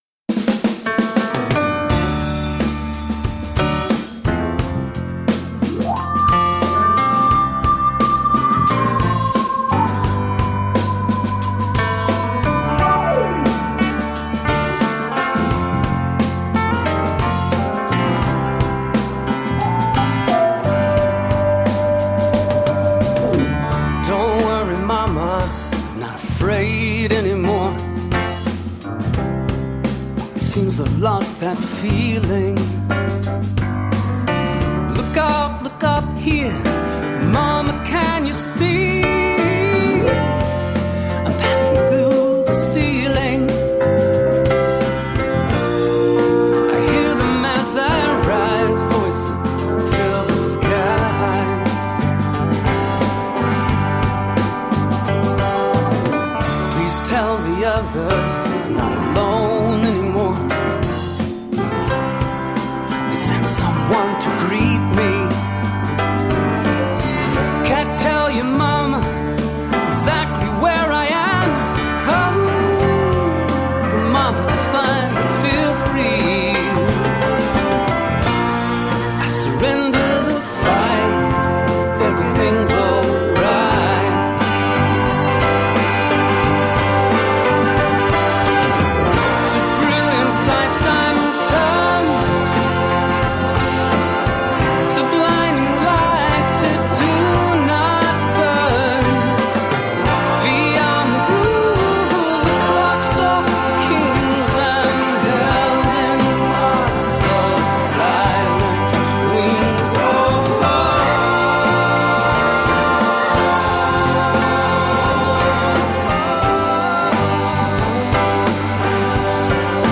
The blues-tinged, ruggedly individualistic songs
is a surprisingly uplifting and joyful meditation on death
Tagged as: Alt Rock, Rock, Folk